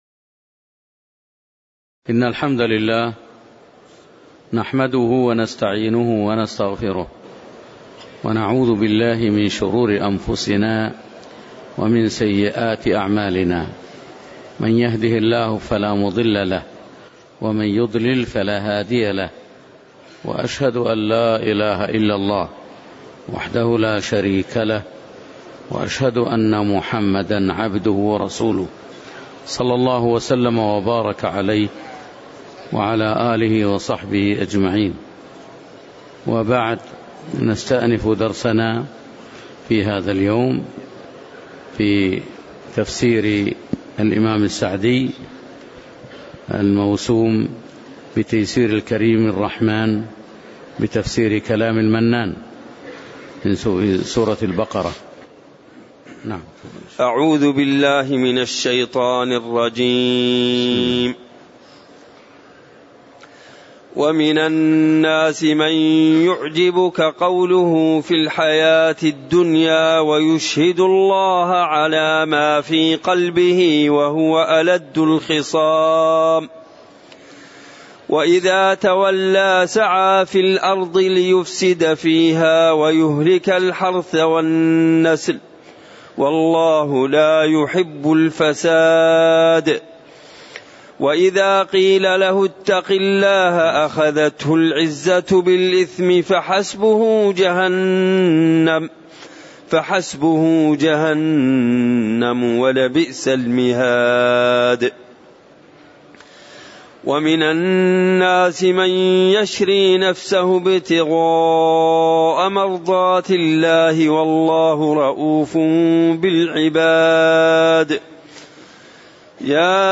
تاريخ النشر ١٧ ذو الحجة ١٤٣٨ هـ المكان: المسجد النبوي الشيخ